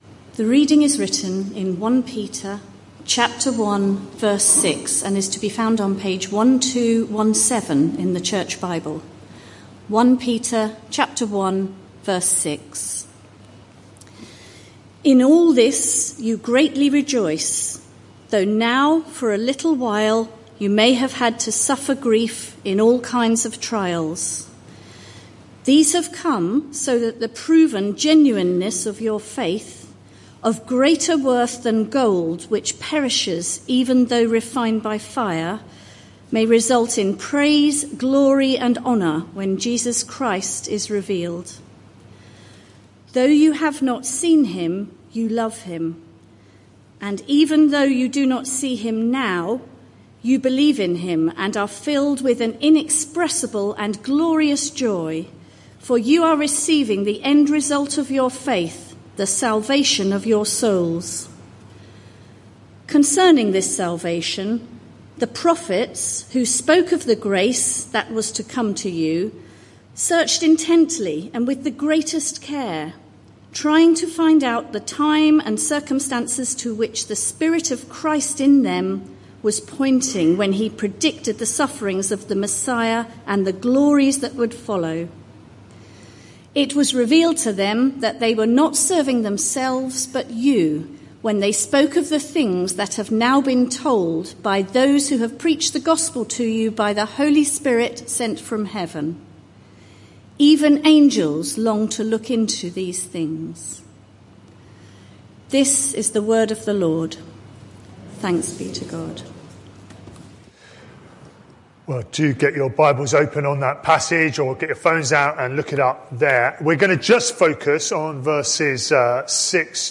(Morning)
This sermon is part of a series